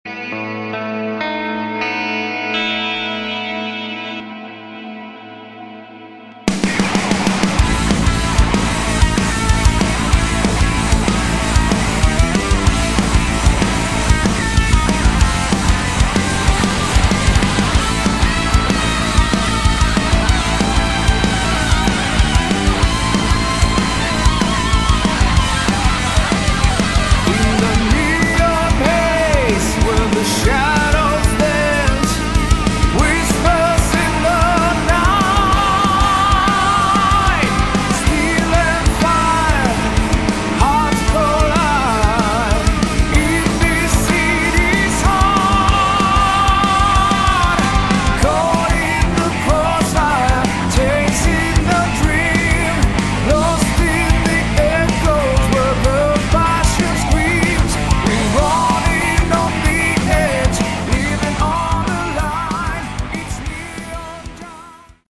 Category: Hard Rock
Vocals
Guitars
Bass
Keyboards, additional guitars
Drums